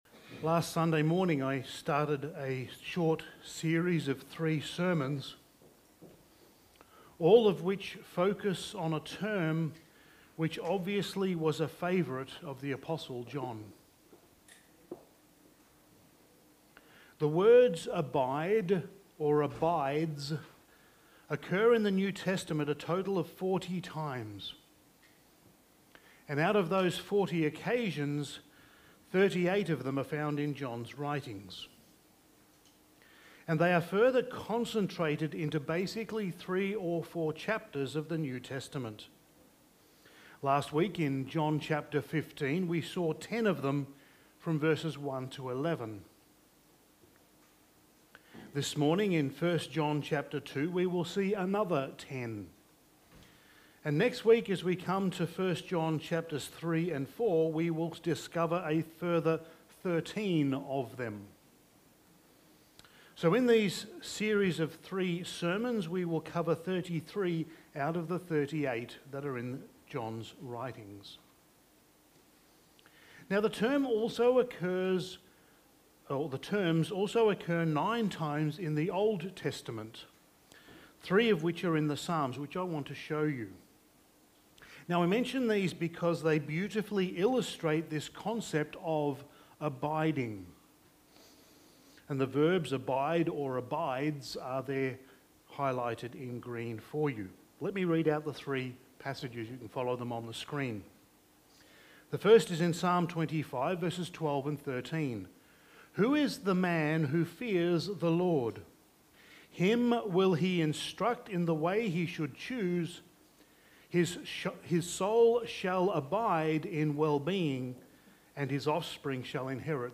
Passage: 1 John 2:1-27 Service Type: Sunday Morning